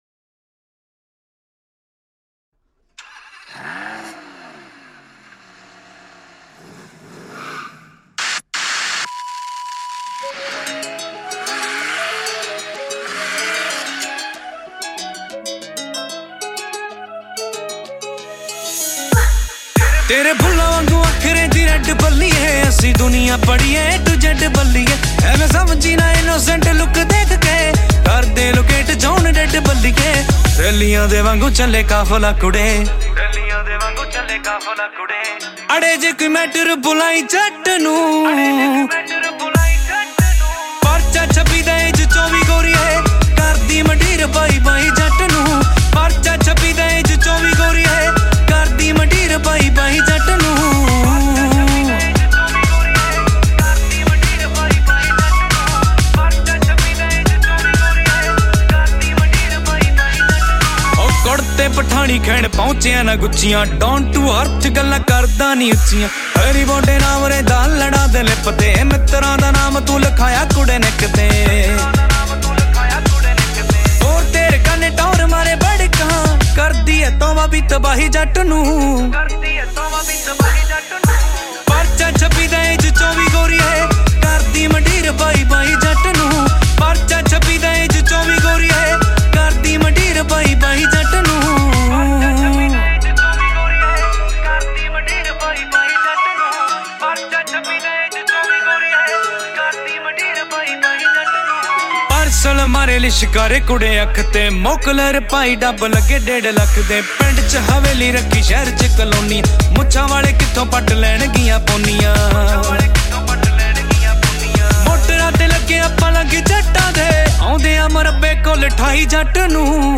New Punjabi Song 2023